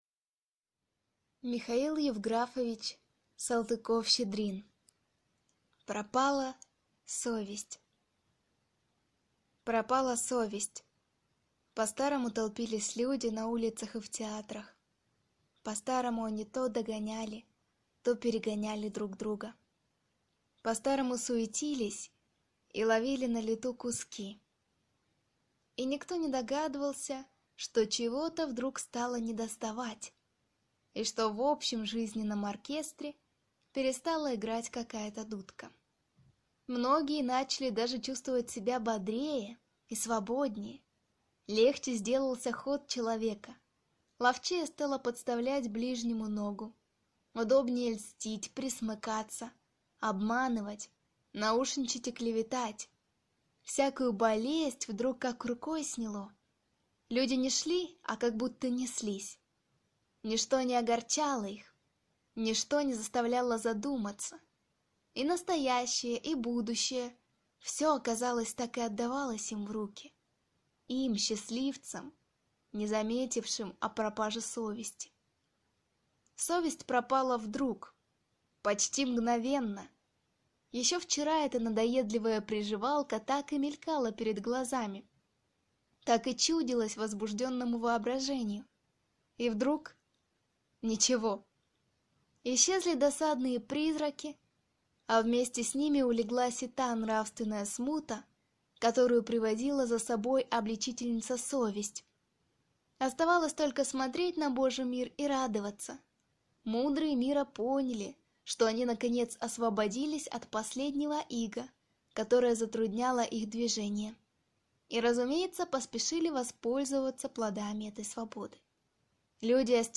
Аудиокнига Пропала совесть | Библиотека аудиокниг